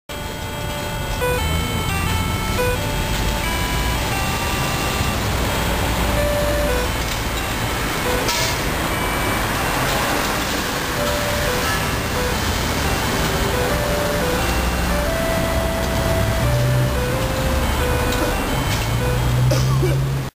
雨の中で、収録中に車が通るたびにシャーという音が入るため実際行きたくないながらも行きましたが、結構２１号以外がありました。
音程は日本信号っぽく聞こえてしまいます。